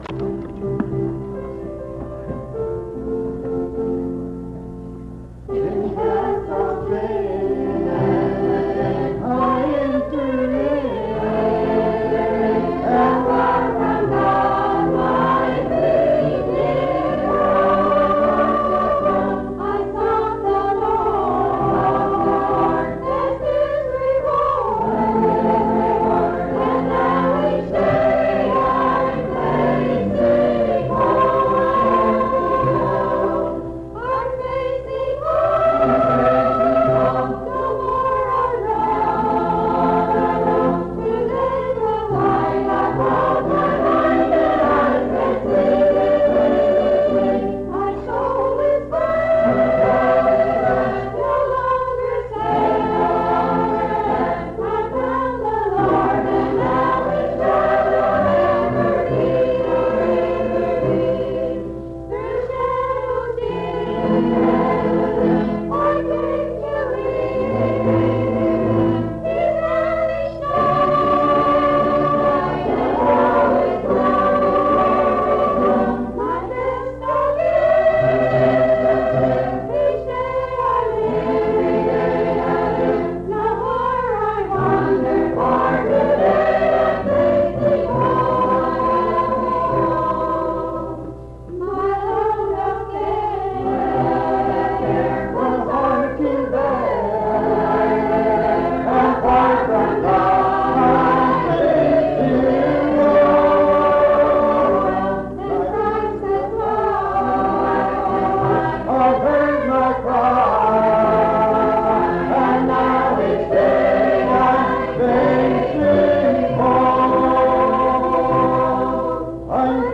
I'm Facing Home Item 2f4b883f6a9eb85d913acd200b5d7ba26073e9ba.mp3 Title I'm Facing Home Creator Highland Park Methodist Church Choir Description This recording is from the Monongalia Tri-District Sing.